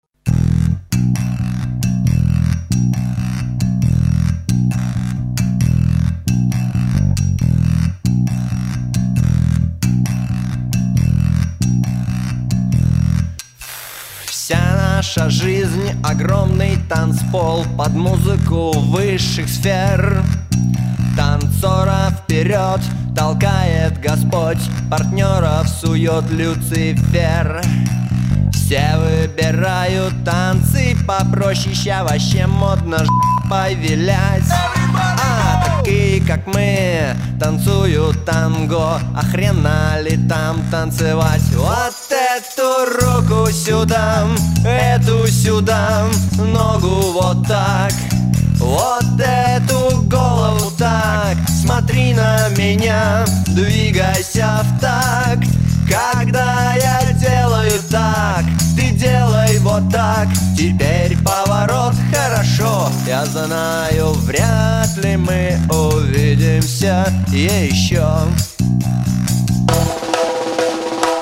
Ласковый драйв, танцевальные ритмы, романтика.